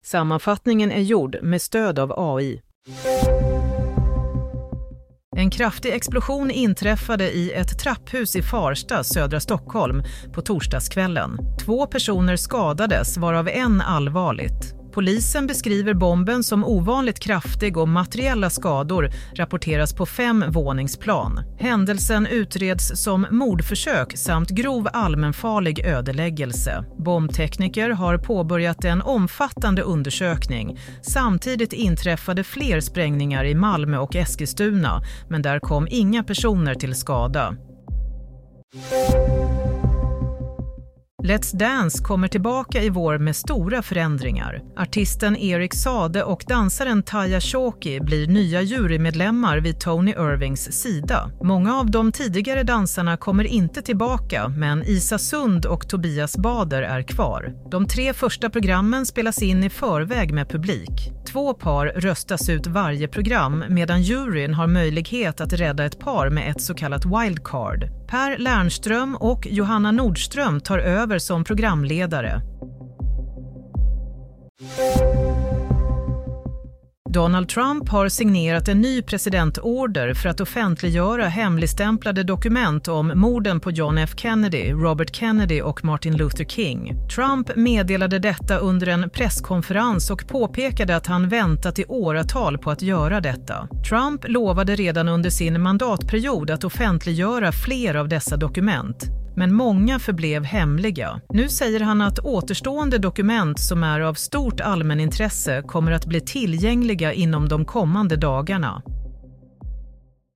Nyhetssammanfattning - 24 januari 07.30